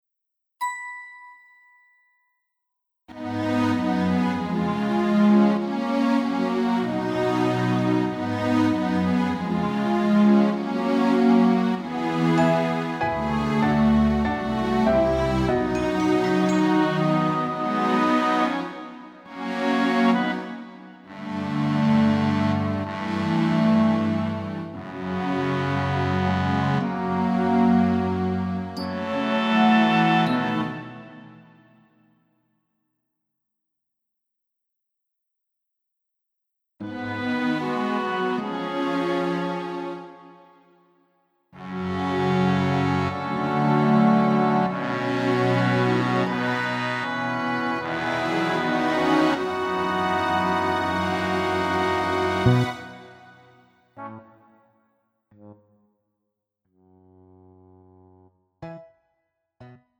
음정 -1키 3:21
장르 가요 구분 Pro MR